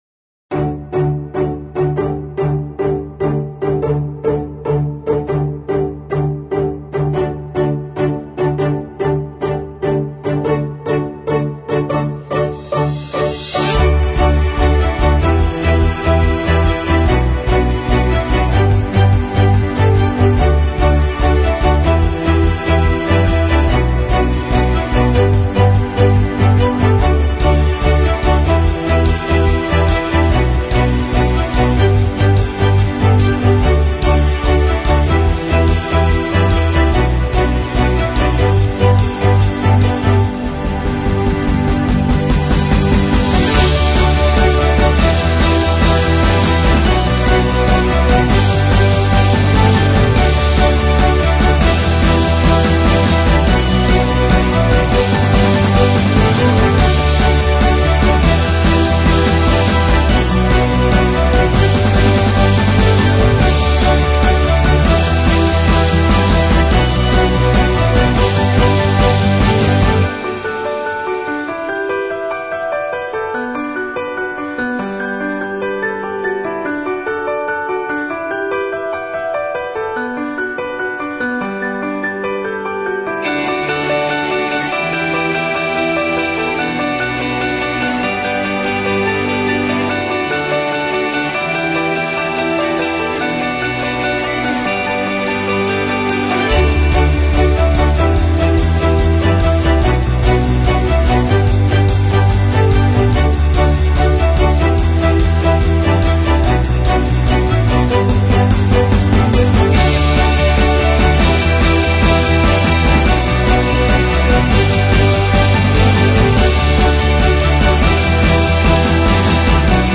Sample Rate 采样率16-Bit Stereo 16位立体声, 44.1 kHz